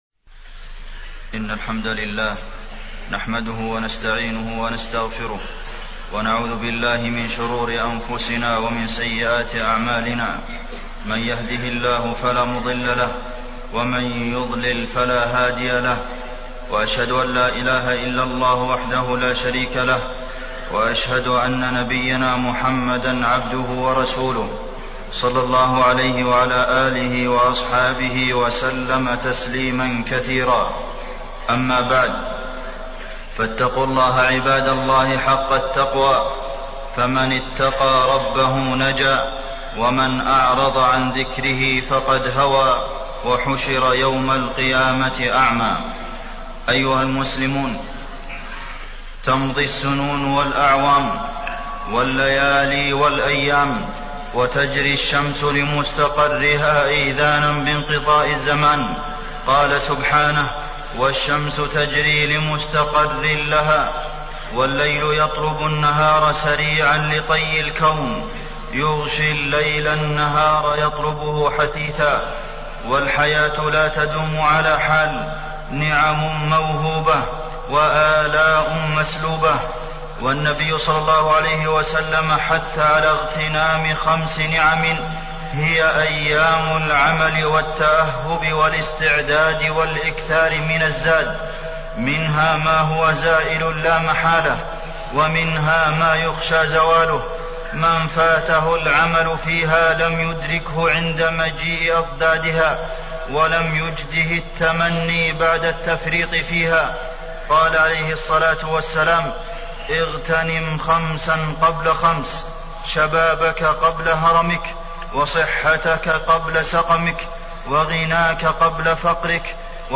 تاريخ النشر ٢٥ ذو الحجة ١٤٢٨ هـ المكان: المسجد النبوي الشيخ: فضيلة الشيخ د. عبدالمحسن بن محمد القاسم فضيلة الشيخ د. عبدالمحسن بن محمد القاسم مرحلة الشباب وأهميتها